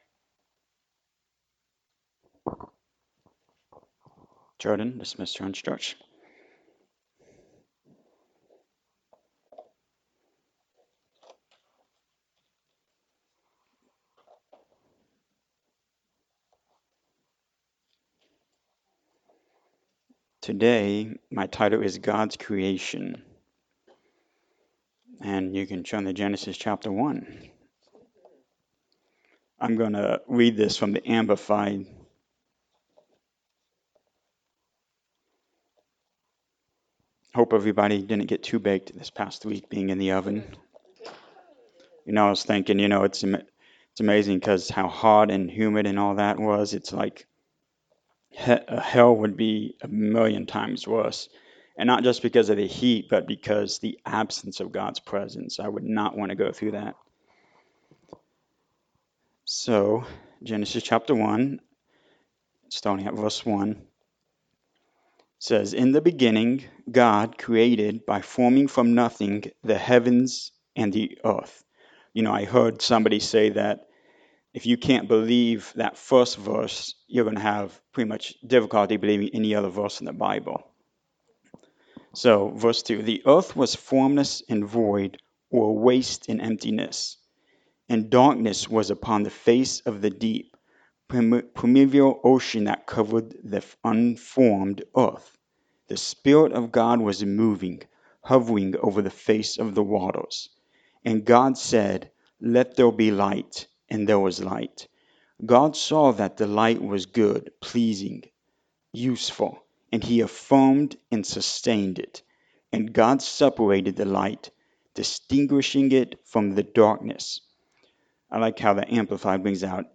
Genesis 1:1-31 Service Type: Sunday Morning Service God’s creation.